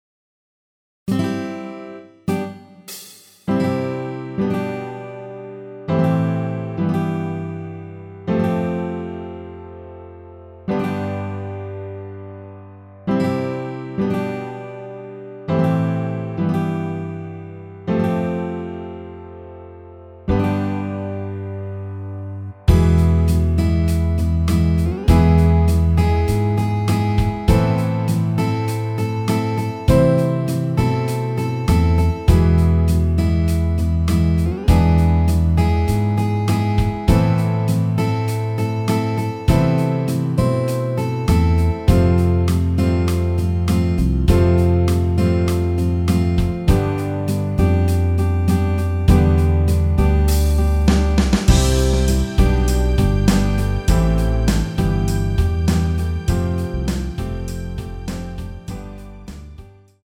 원키에서(-3)내린 MR입니다.
전주 없이 시작 하는 곡이라 1마디 드럼(하이햇) 소리 끝나고 시작 하시면 됩니다.
앞부분30초, 뒷부분30초씩 편집해서 올려 드리고 있습니다.
중간에 음이 끈어지고 다시 나오는 이유는